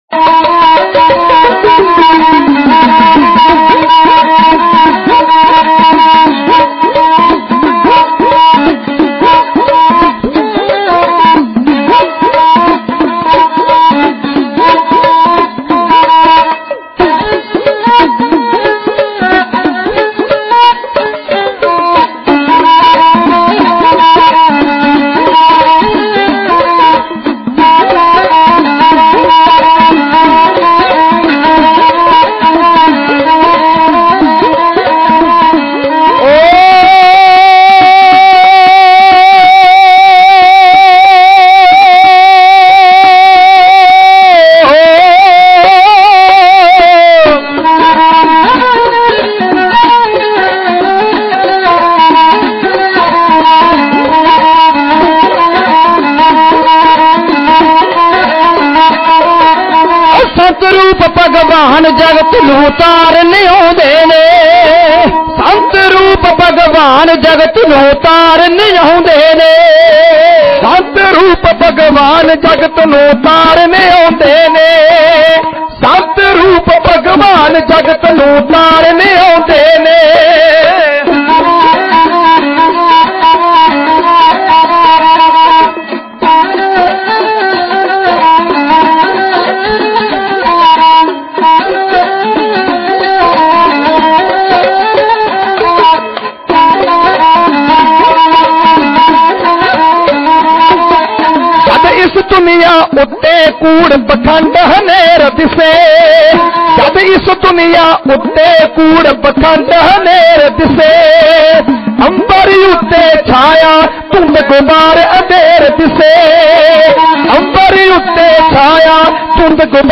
Genre: Dhadi Vaara